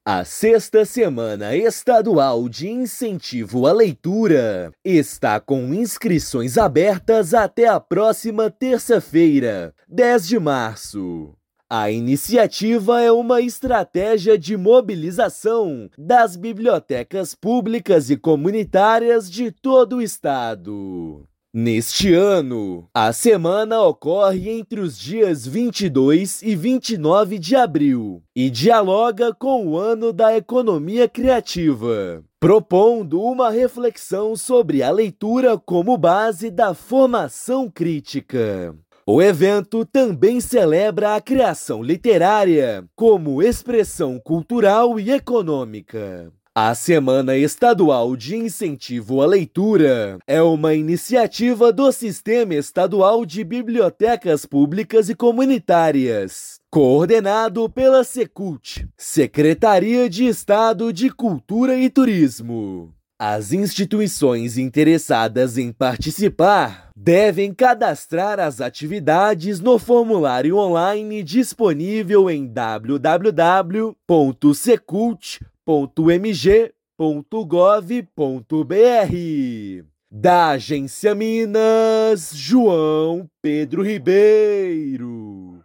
Iniciativa reafirma o papel da literatura como direito cultural e instrumento de conhecimento, expressão e transformação social. Ouça matéria de rádio.